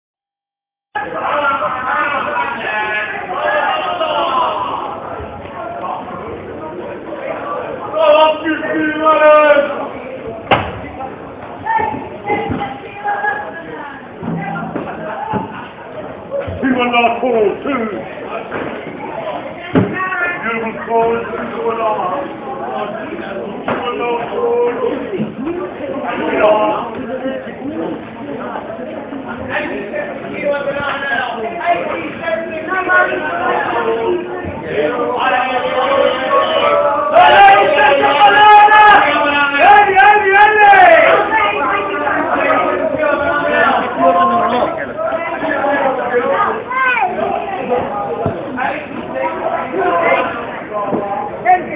Sounds of the Veggiemongers
Weird sounds of the Veggiemongers at Victoria Market in Melbourne.